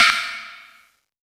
SOUTHSIDE_percussion_metal_or_wood.wav